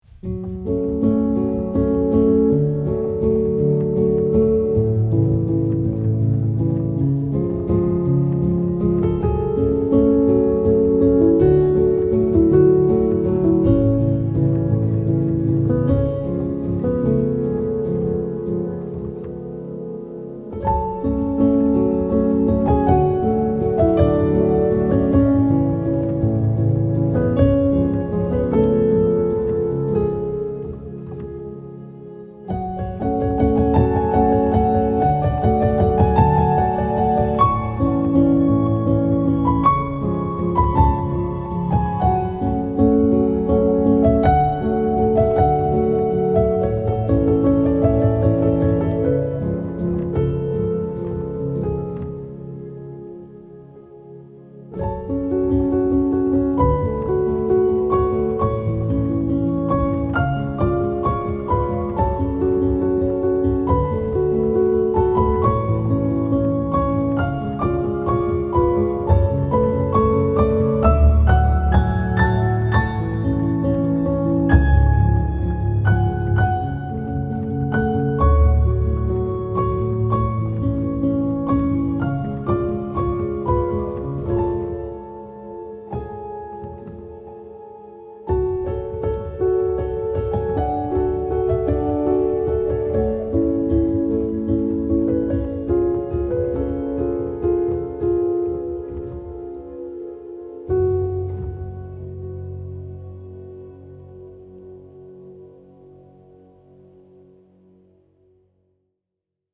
night-piano.mp3